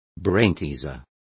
Complete with pronunciation of the translation of brainteasers.